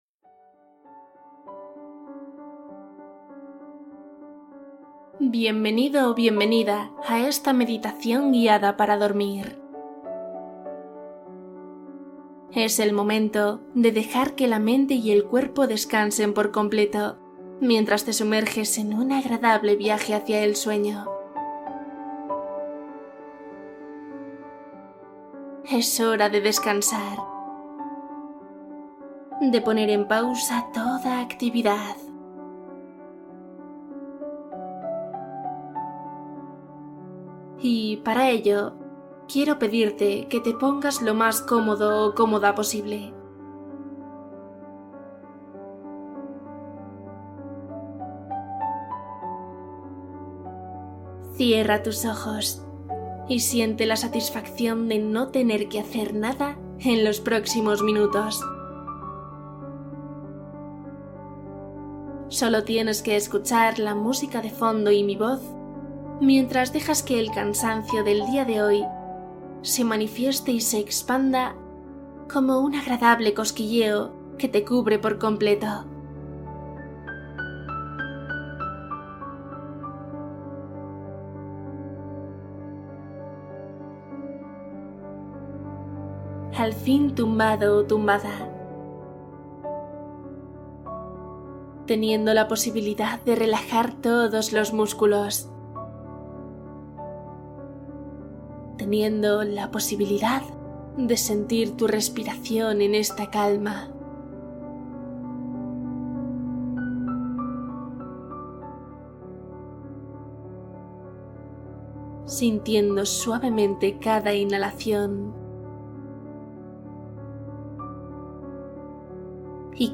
Meditación nocturna con historia para soñar profundamente